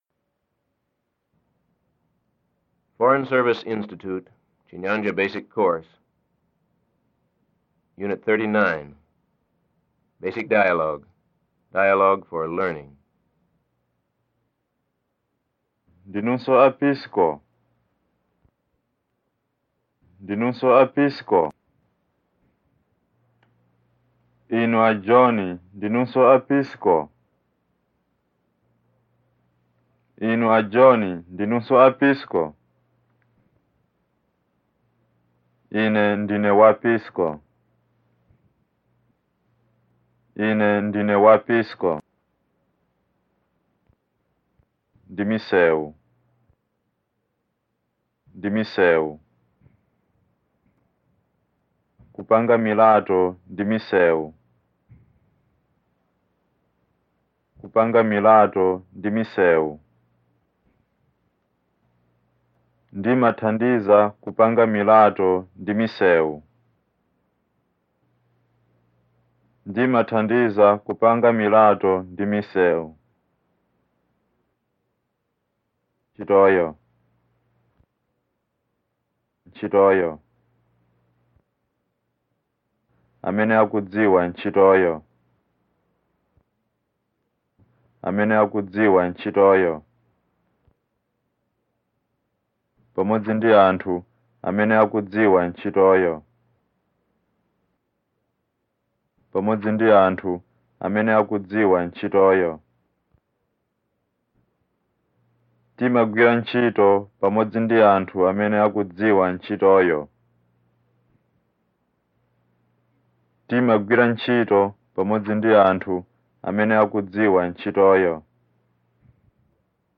06-01-Chinyanja-Basic-Course.mp3